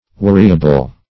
Weariable \Wea"ri*a*ble\, a.